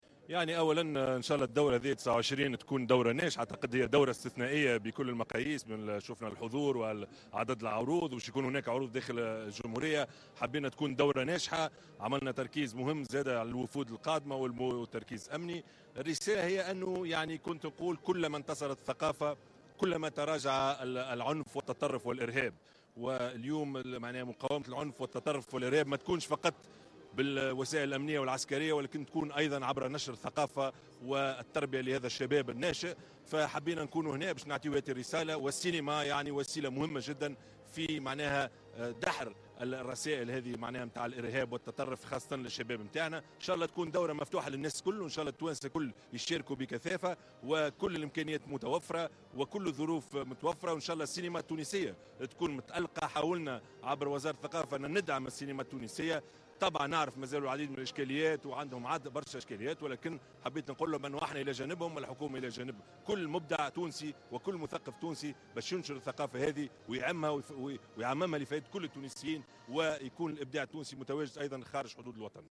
وشدّد في تصريح صحفي على هامش افتتاح أيام قرطاج السينمائية بمدينة الثقافة بالعاصمة على أهمية دور الثقافة والسينما كوسيلة مهمة لدحر الإرهاب، وفق تعبيره.